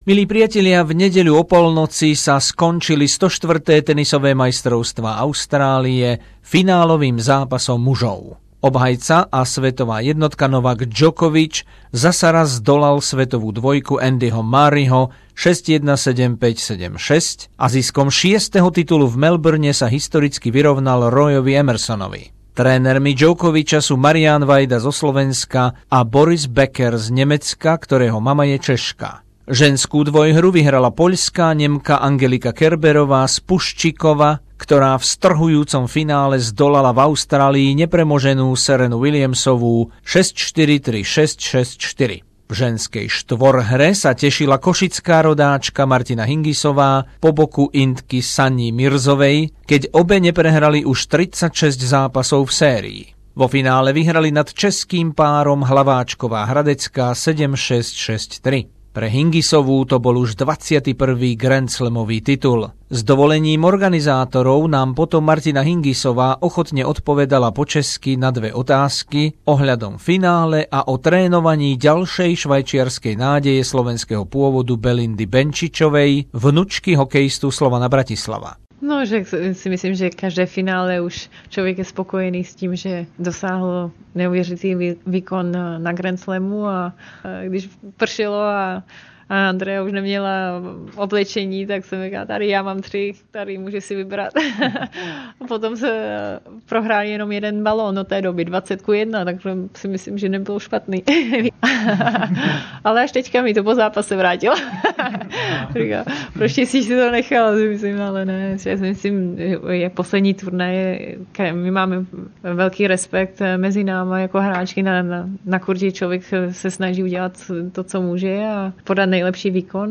Rozhovory po záverečnom dni Australian Open 2016 v tenise z Arény Roda Lavera